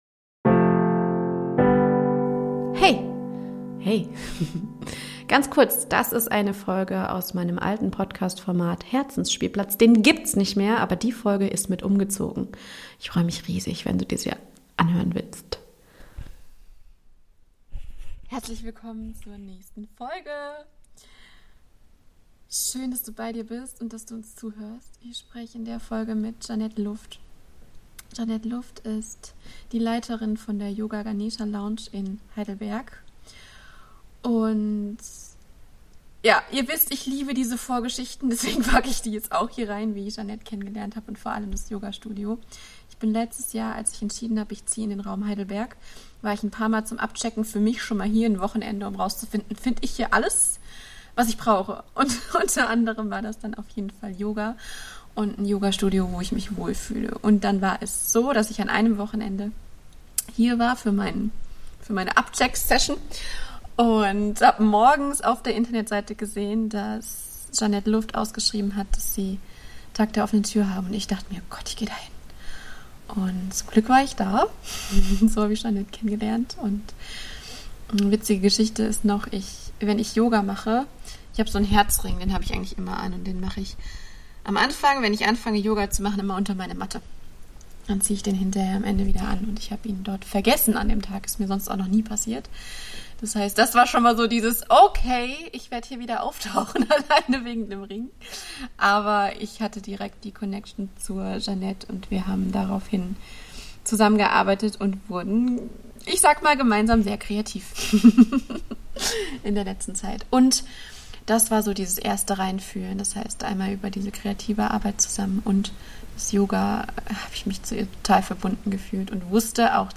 Interview: Yoga, Flowgefühl und was passt wirklich zu mir?